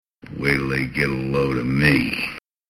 As an aside, it obviously doesn't come across at all in still pictures, but every level is peppered with voice clips direct from the film.